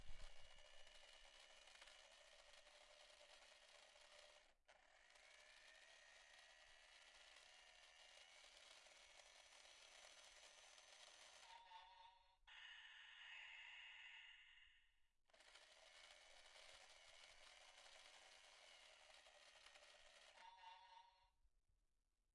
儿童玩具车
描述：儿童玩具